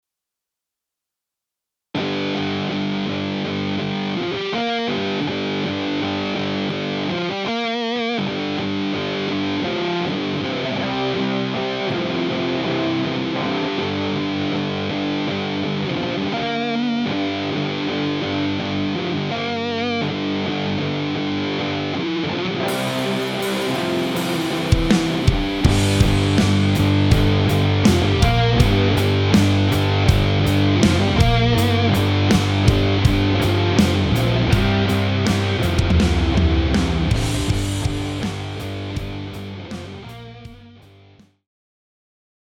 Alternativ mal wieder Nu Metal Kacke.